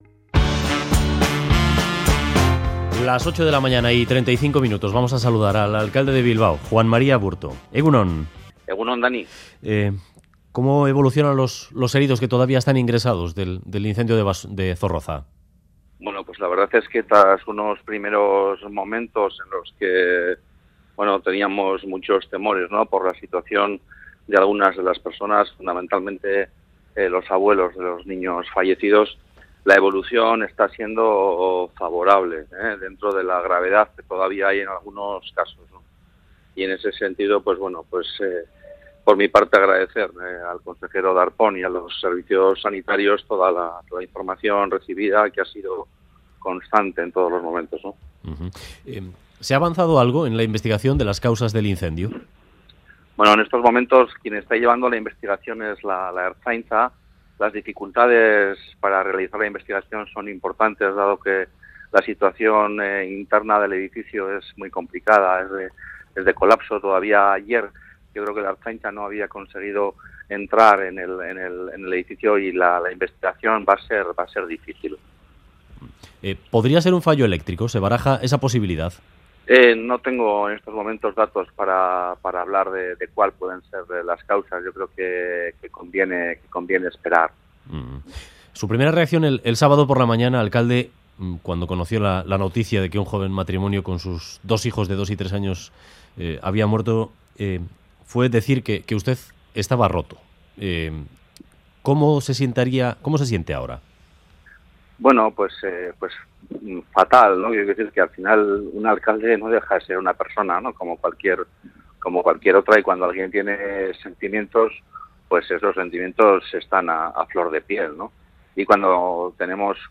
Audio: Aburto, alcalde de Bilbao, afirma que la situación del edificio continúa siendo de colapso, poco se puede anticipar en torno a las causas del incendio.